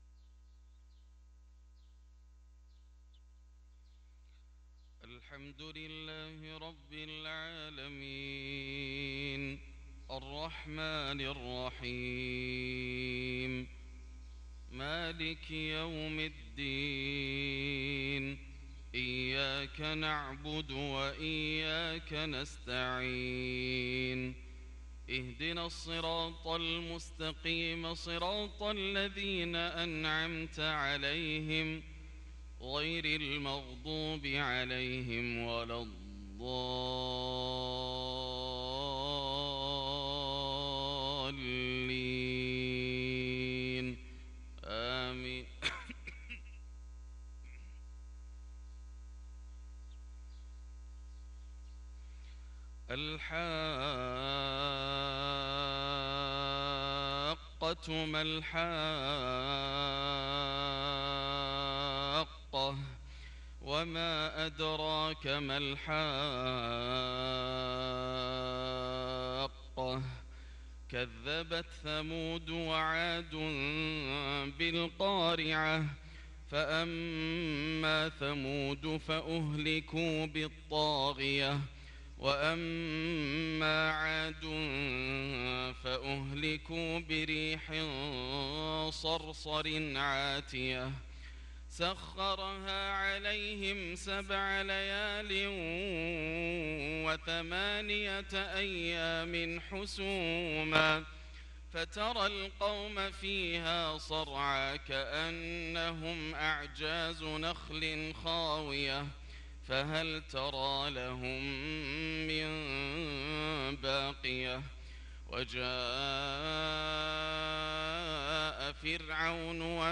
صلاة الفجر للقارئ ياسر الدوسري 3 ربيع الآخر 1444 هـ
تِلَاوَات الْحَرَمَيْن .